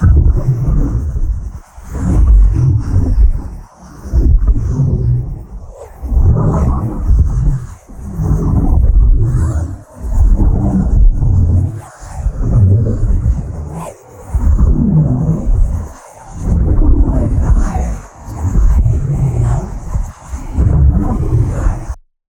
ghost_idle.ogg